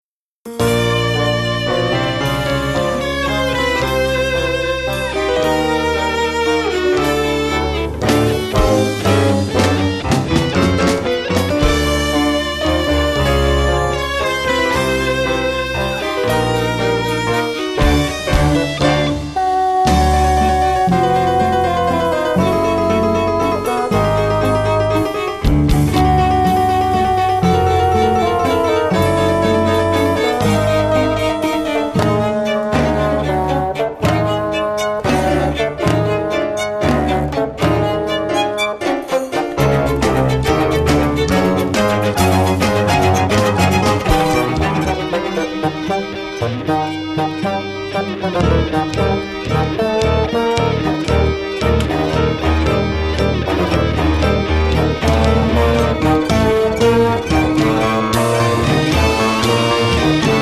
chamber music, rock, theatre and improvisation
Violin, cello, bassoon, saxes and accordion